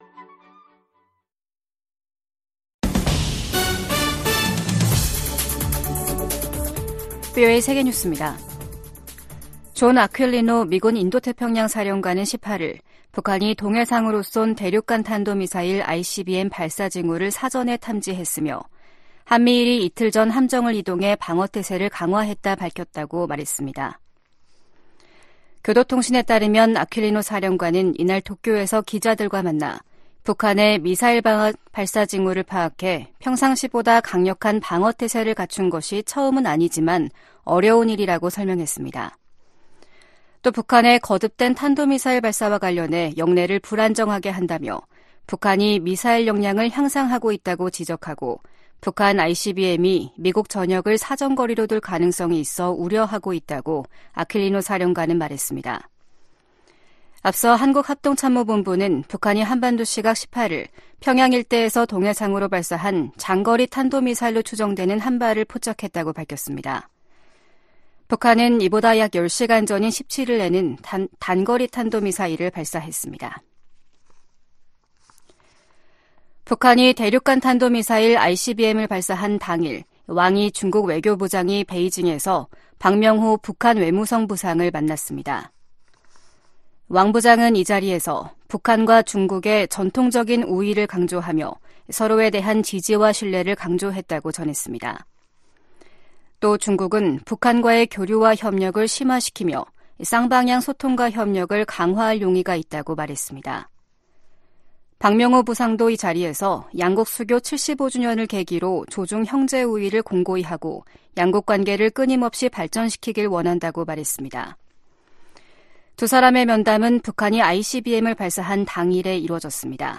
VOA 한국어 아침 뉴스 프로그램 '워싱턴 뉴스 광장' 2023년 12월 19일 방송입니다. 북한이 단거리 탄도 미사일에 이어 18일 대륙간 탄도미사일을 시험발사했습니다. 미한일 3국 안보 수장들이 북한의 대륙간탄도미사일(ICBM) 발사를 규탄했습니다. 미국과 한국은 이에 앞서 확장억제 강화 방안을 논의하는 핵협의그룹(NCG) 2차 회의를 열고 북핵 사용에 대한 강력한 경고 메시지를 발신했습니다.